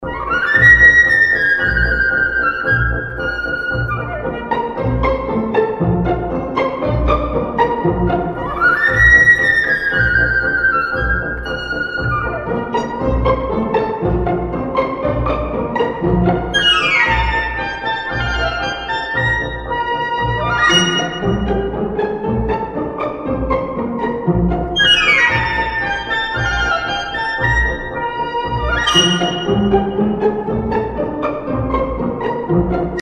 Рингтоны » Классические